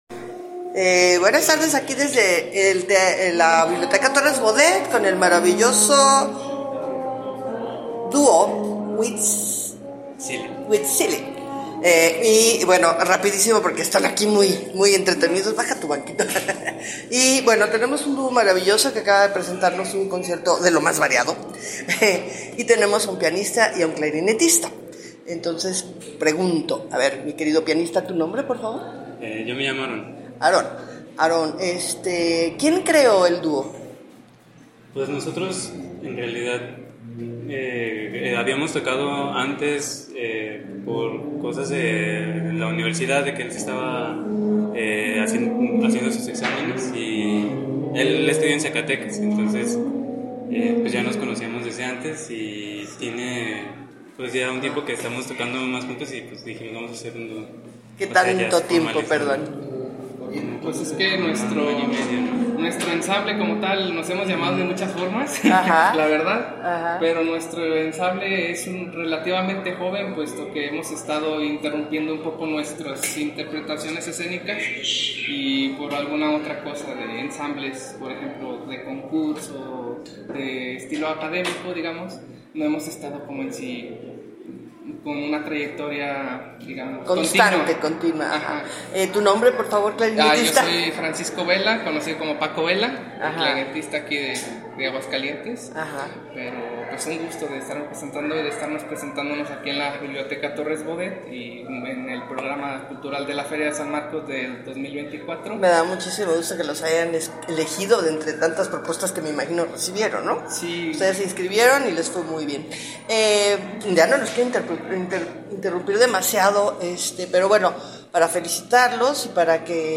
Sugiero escuche la entrevista realizada por aescena a este gran dúo, quienes ya llevan una trayectoria de cinco años.